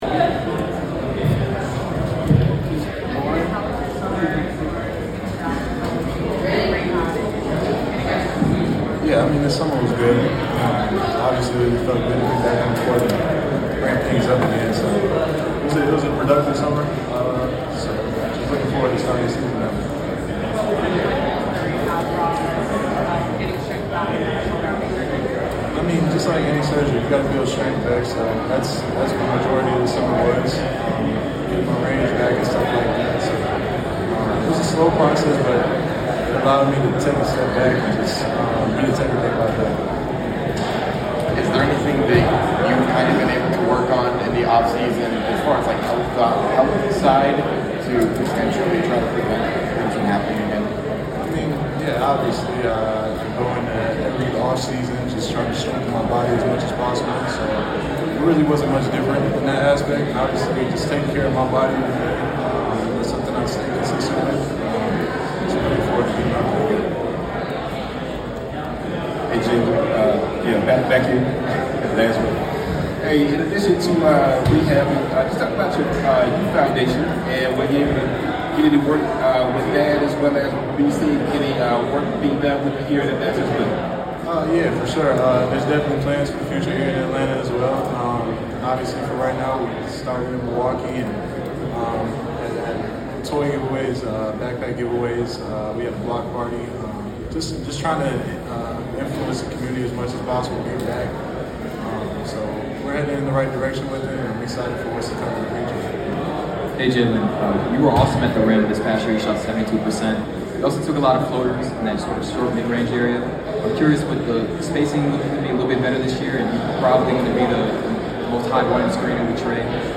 Atlanta Hawks Forward Jalen Johnson 2025 Media Day Press Conference at PC&E.